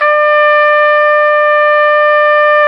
Index of /90_sSampleCDs/Roland LCDP12 Solo Brass/BRS_Tpt _ menu/BRS_Tp _ menu